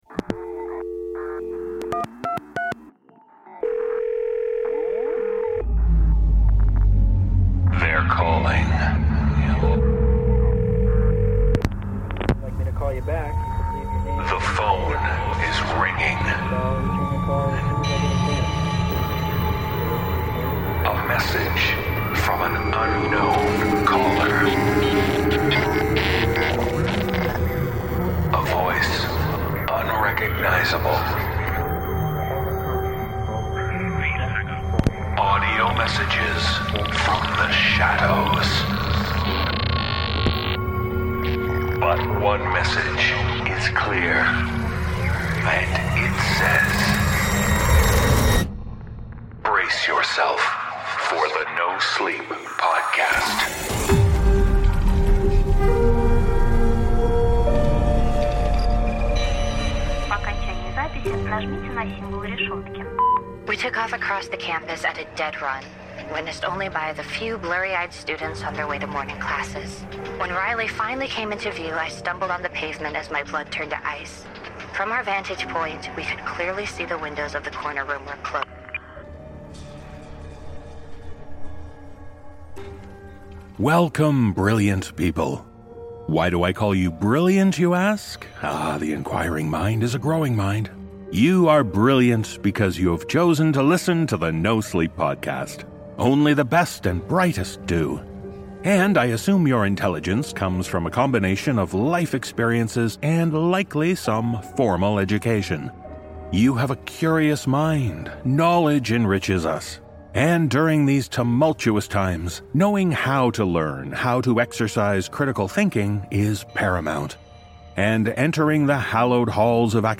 The voices are calling with tales of collegiate carnage.